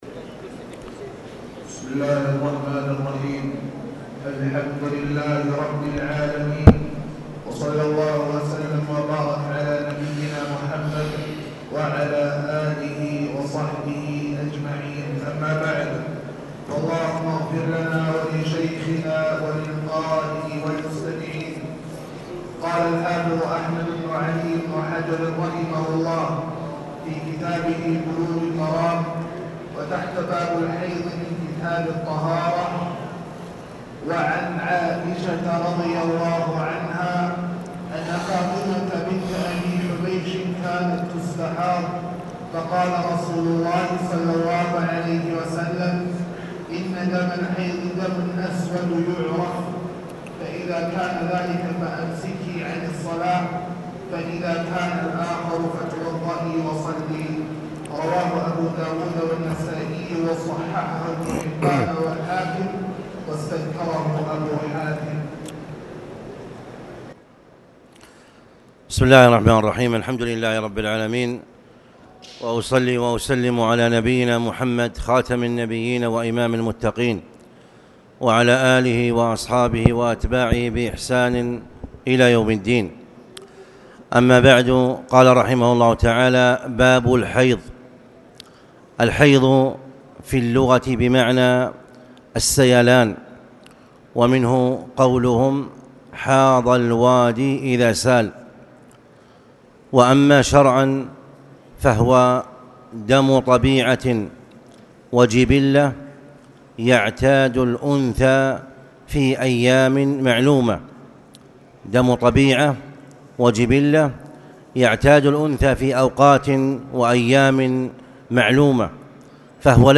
تاريخ النشر ٢٥ جمادى الأولى ١٤٣٨ هـ المكان: المسجد الحرام الشيخ